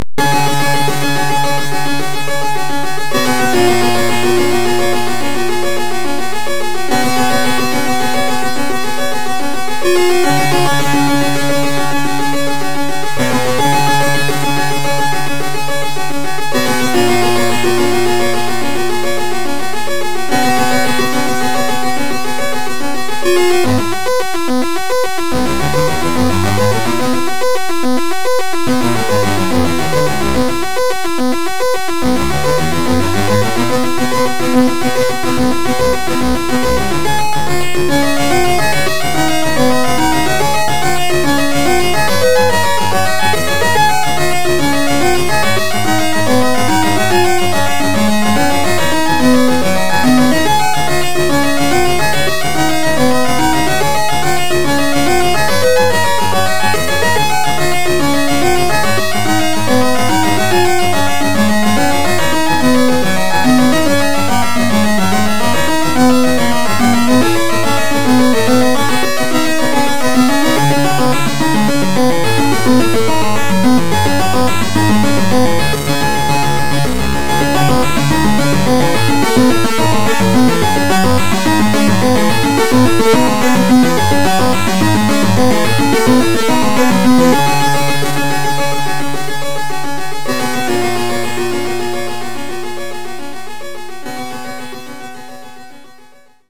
– a Commodore 64 tune I found and burned.